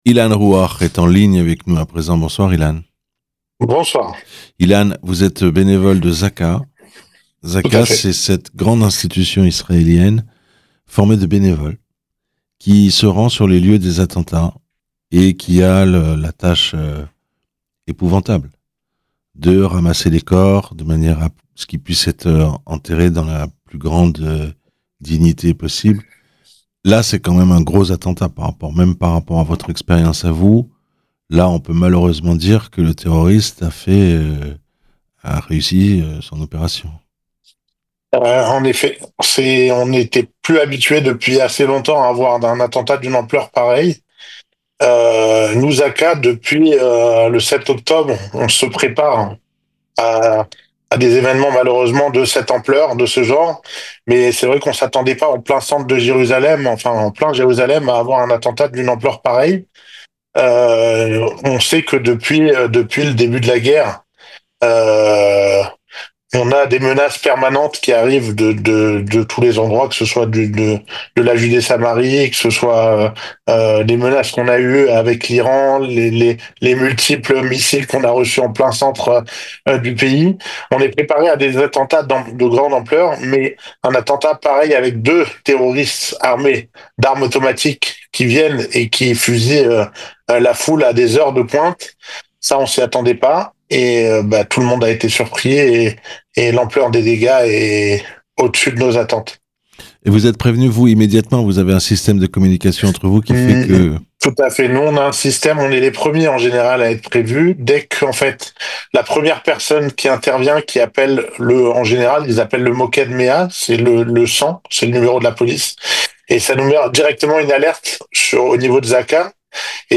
Attentat de Jerusalem. Le témoignage d'un bénévole de Zaka